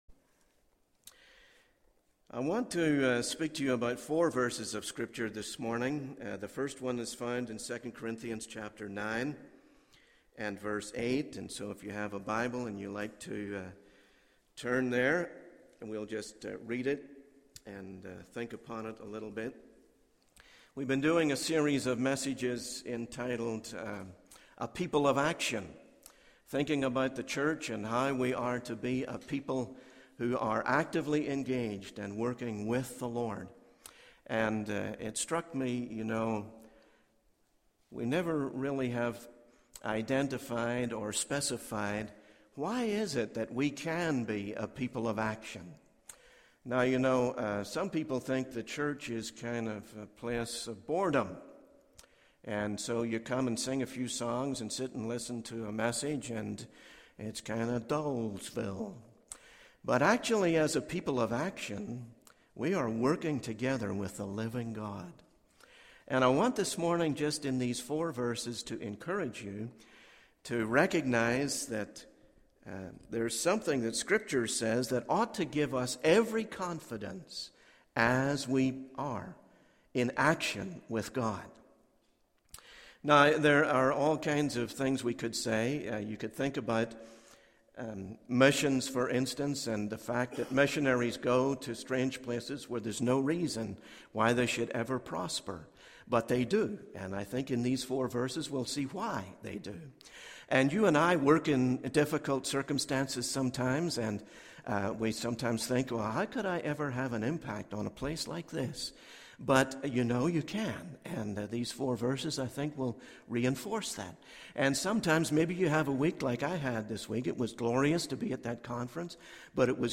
In this sermon, the preacher discusses the idea of being a people of action in the church. He emphasizes that the church is not a place of boredom, but rather a place where believers actively work with the living God. The preacher encourages the congregation to have confidence in their actions with God, citing 2 Corinthians 9:8 as a source of encouragement.